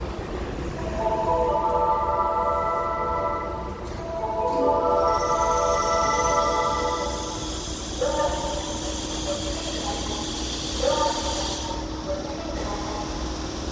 ・2000系車載メロディ（〜2023/3頃）
北行きの物は車載および埼玉高速線内の各駅では間隔が短いのも特徴でした（尚、この曲はスイッチ管理ではありません）。